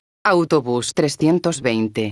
megafonias exteriores
autobus_320.wav